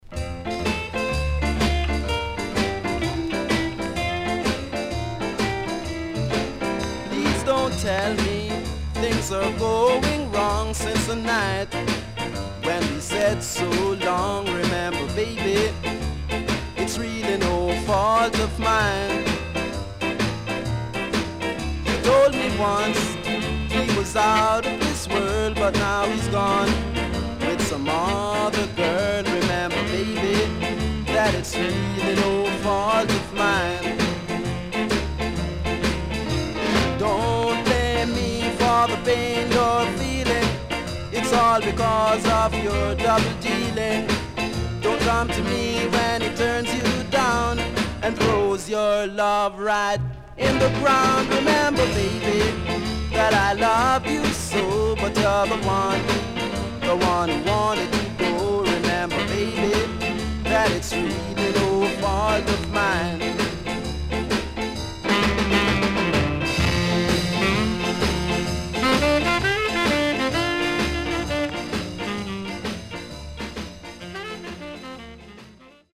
HOME > SKA / ROCKSTEADY  >  SKA  >  EARLY 60’s
SIDE A:少しプチノイズ入ります。